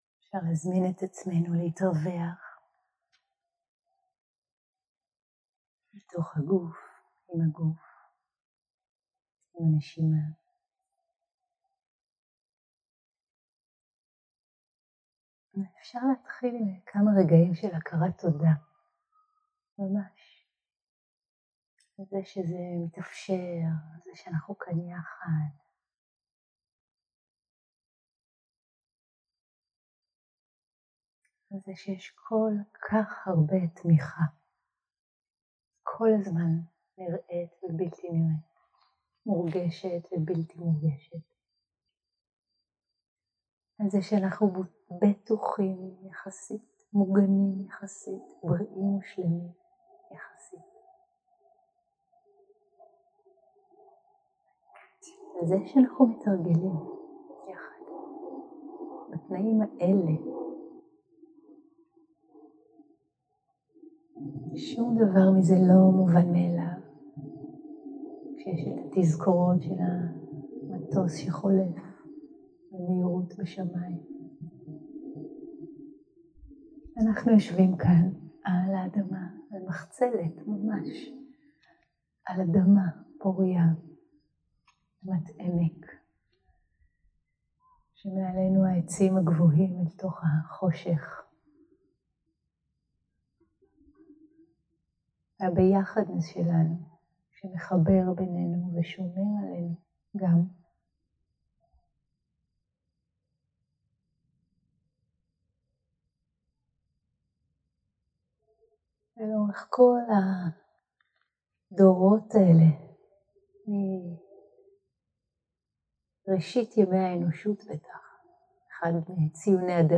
יום 5 - הקלטה 13 - ערב - הנחיות למדיטציה - על נדיבות כמטה הקסם - שיחה, לימוד ומדיטציה 0:00 0:00 סוג ההקלטה: Dharma type: Guided meditation שפת ההקלטה: Dharma talk language: Hebrew